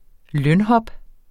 Udtale [ ˈlœn- ]